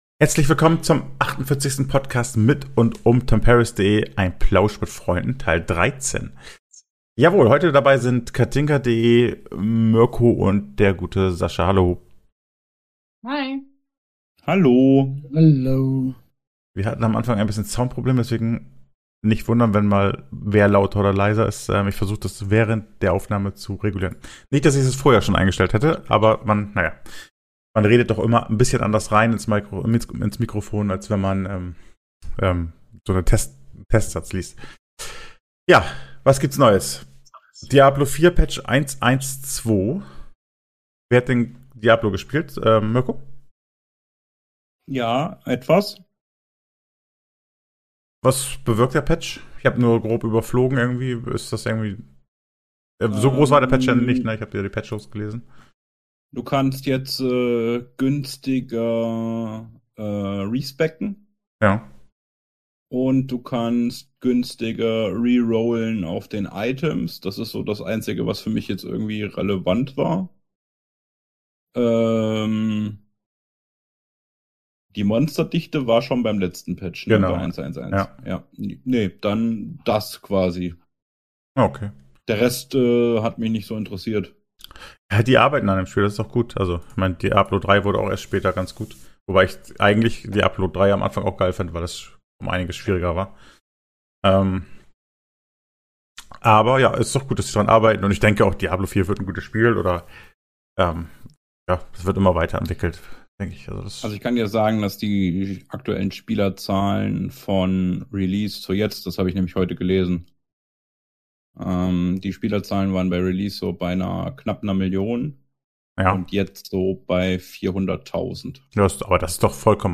Ein Plausch mit Freunden (13)